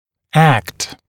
[ækt][экт]акт, действие, процесс; действовать, поступать, оказывать действие